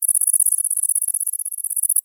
INSECT_Crickets_mono.wav